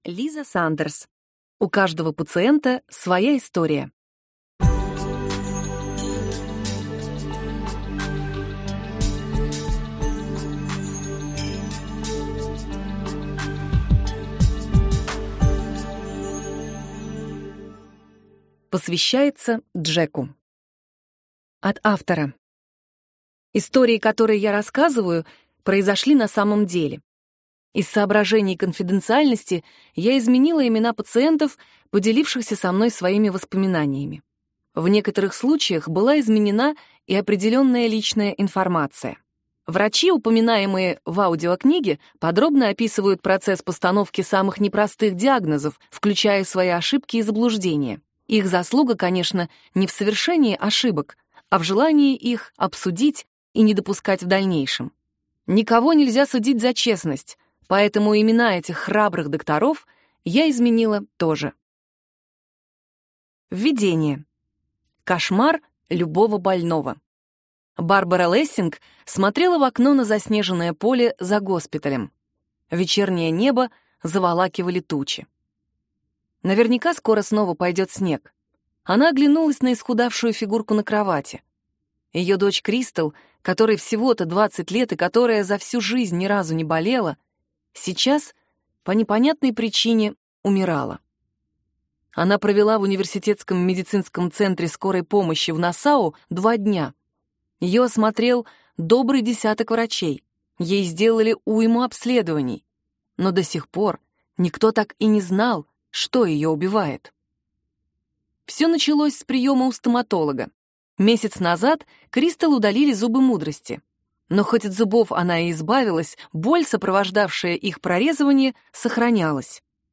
Аудиокнига У каждого пациента своя история | Библиотека аудиокниг